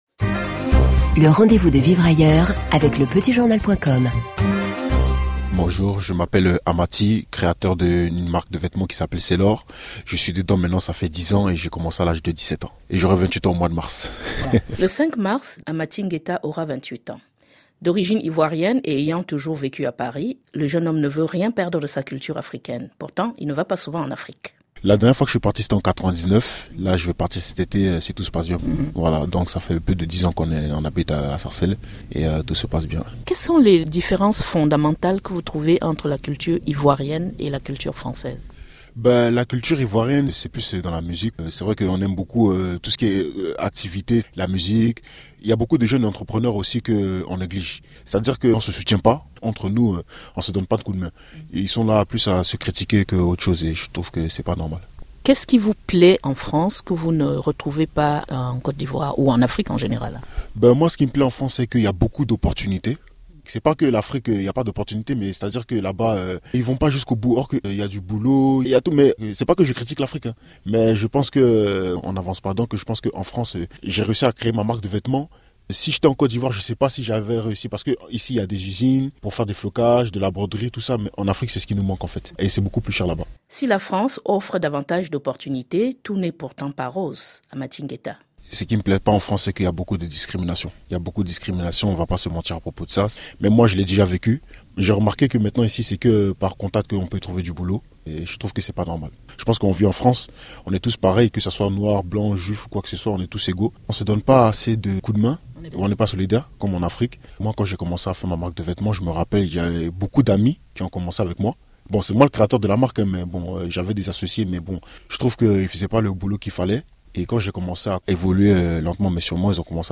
– Ecouter un créateur présenter sa marque